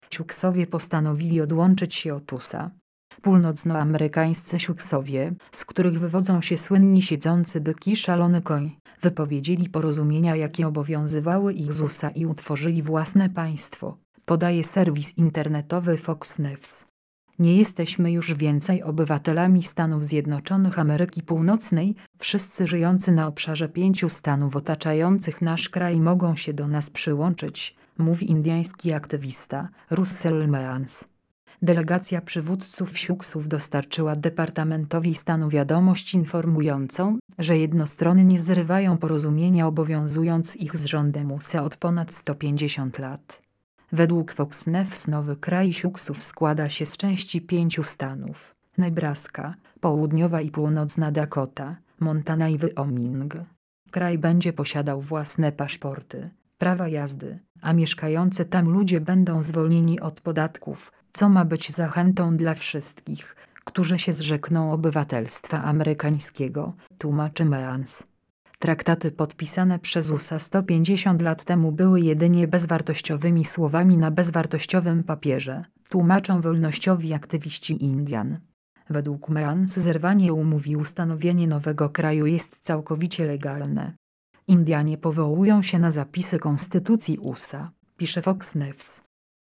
Odczyt